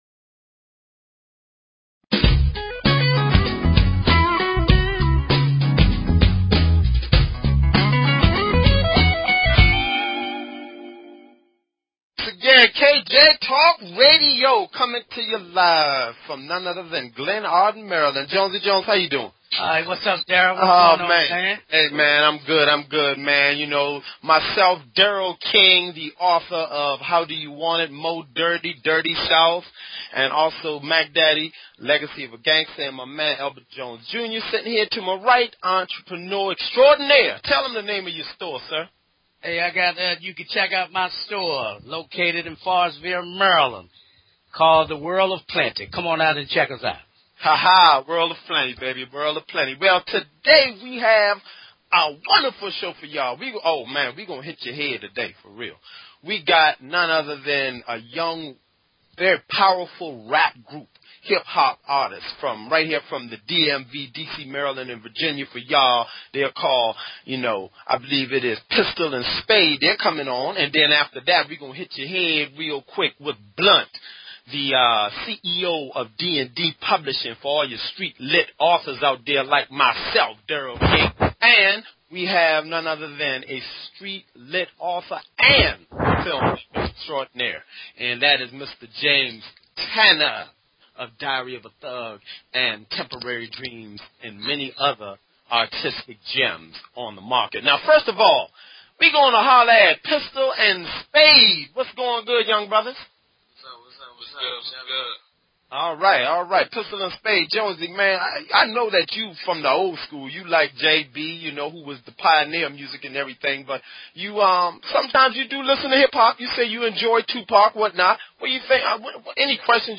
Talk Show Episode, Audio Podcast, KJ_Talk_Radio and Courtesy of BBS Radio on , show guests , about , categorized as
KJ Talk radio is an un opinionated, and open forum which provides a platform for a wide variety of guests, and callers alike.